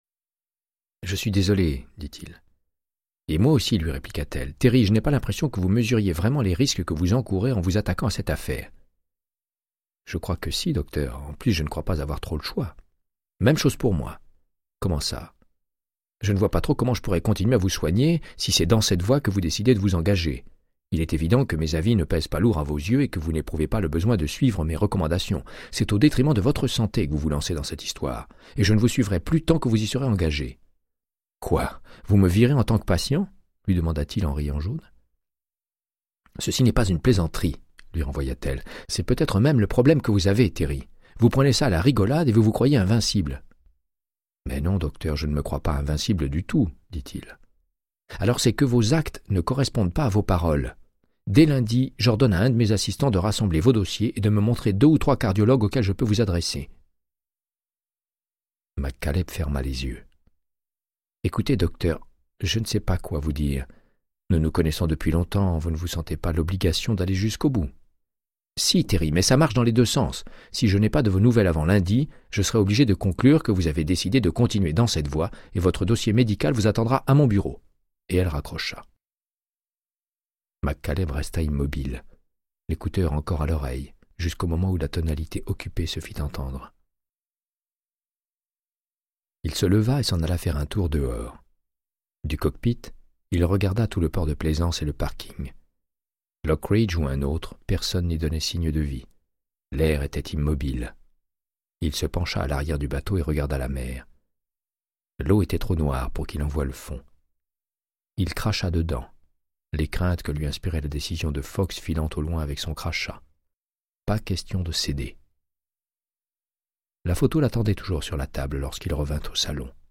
Audiobook = Créance de sang, de Michael Connellly - 75